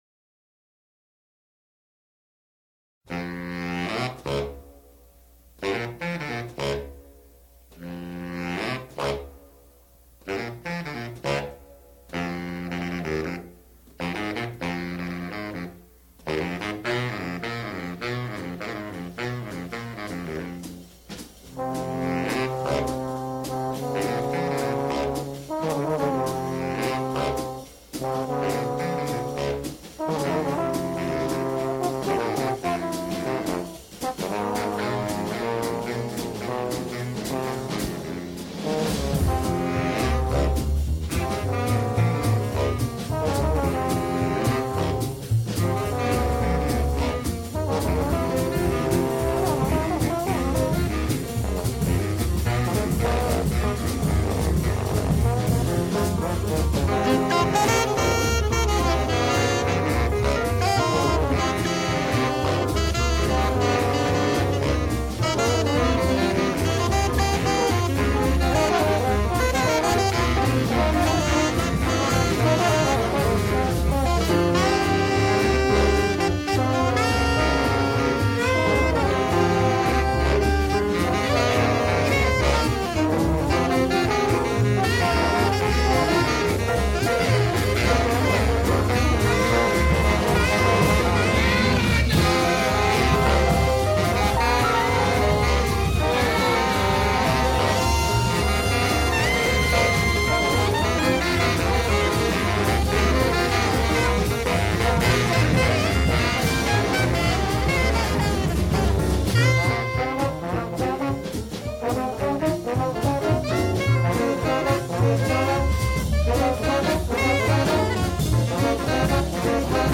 Jazz
Bass
Alto sax
Tenor sax
Baritone sax
Trombone
Drums
Piano